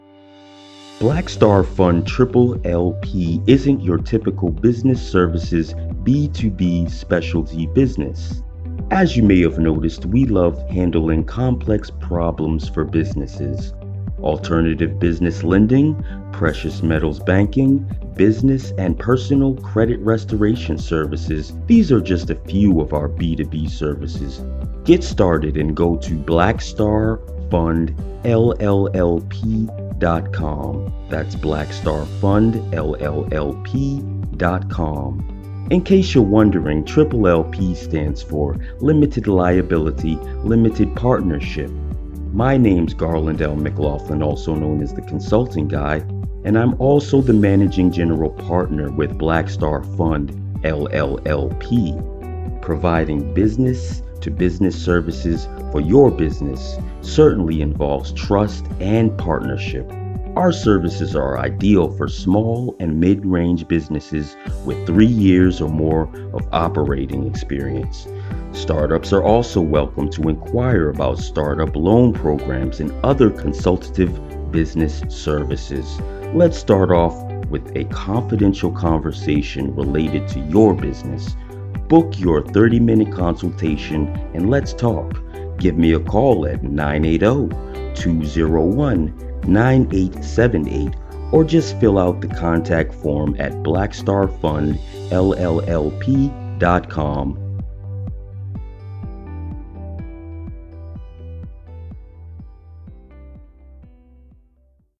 Radio Commercial